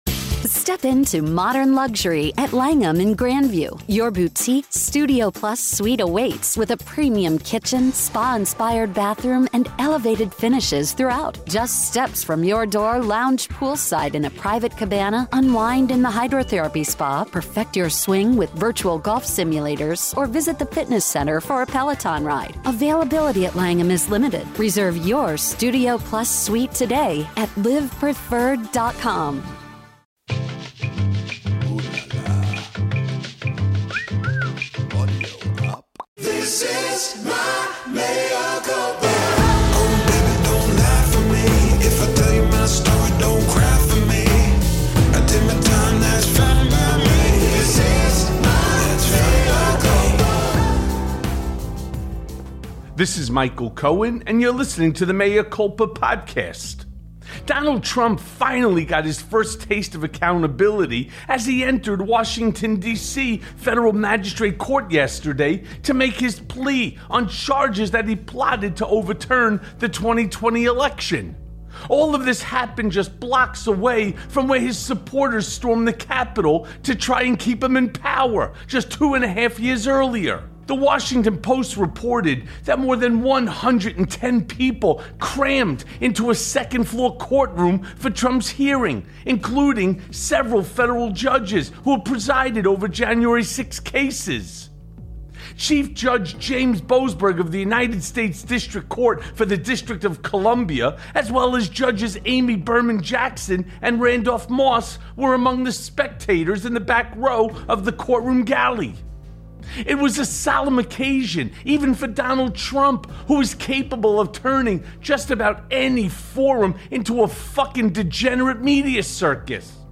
Mea Culpa welcomes back, intrepid newsman, Ali Velshi.